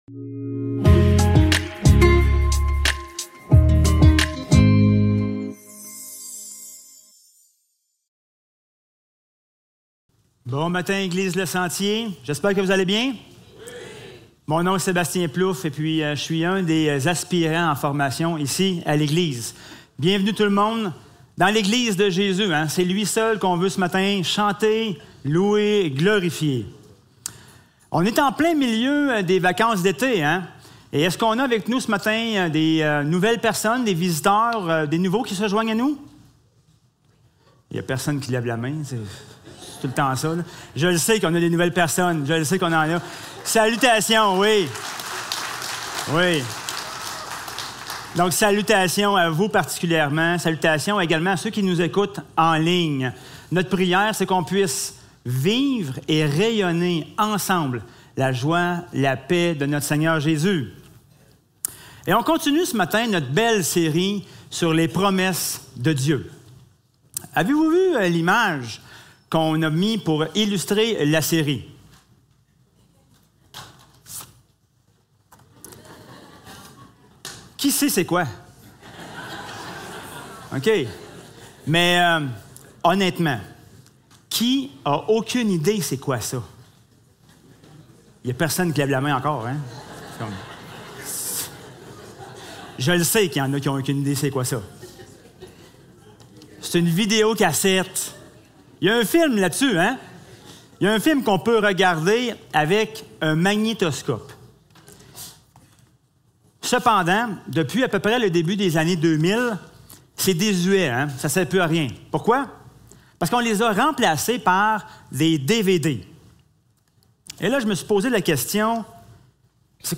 Jean 3.16 Service Type: Célébration dimanche matin Description